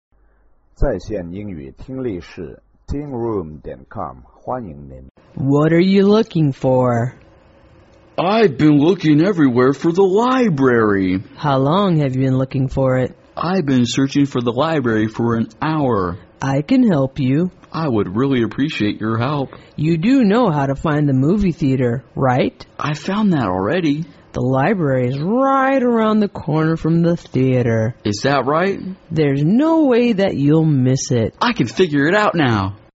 情景英语对话：Looking for the Library(3) 听力文件下载—在线英语听力室